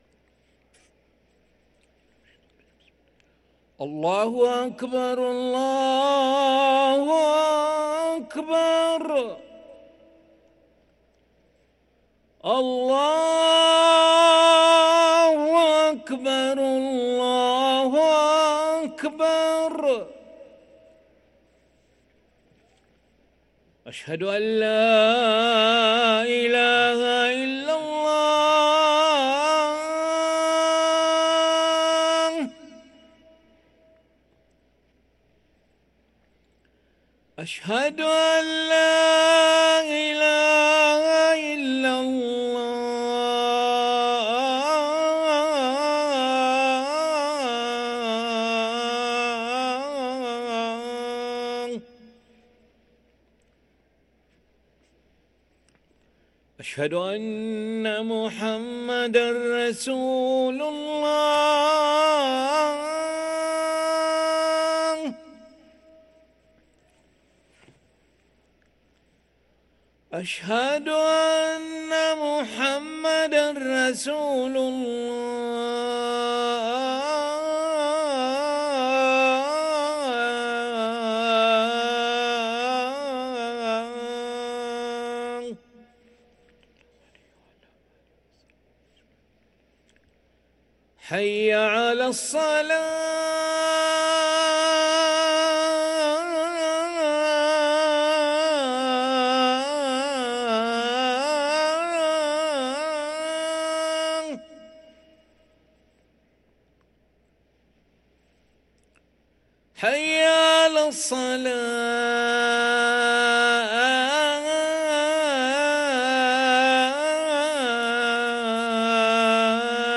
أذان العشاء للمؤذن علي أحمد ملا الخميس 5 ذو القعدة 1444هـ > ١٤٤٤ 🕋 > ركن الأذان 🕋 > المزيد - تلاوات الحرمين